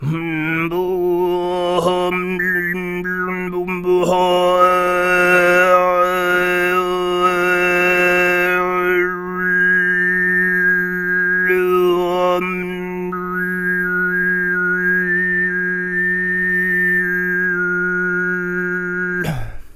喉部唱法 " 喉部唱法诵经西藏密宗 WET
描述：到目前为止，我在喉音演唱方面的进展的录音，加入了混响。
标签： 图瓦喉唱歌 密宗 诵经 藏族诵经 喉咙唱歌
声道立体声